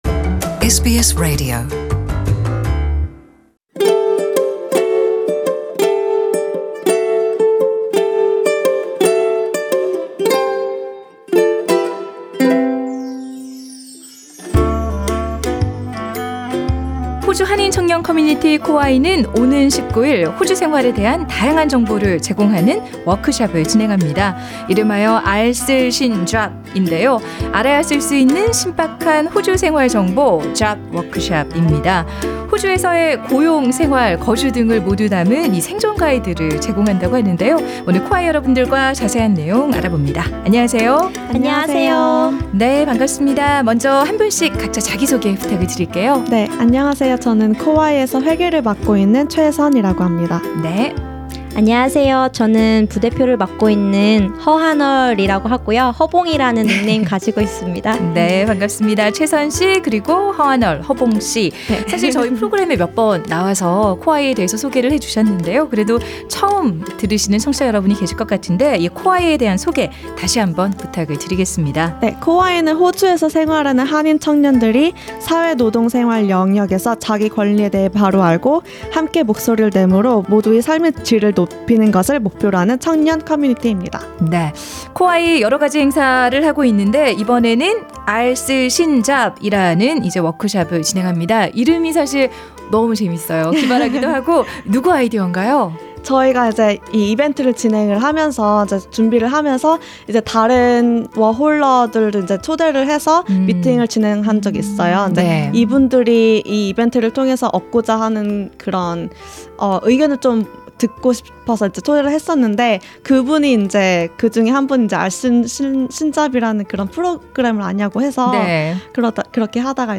The full interview is available on the podcast above.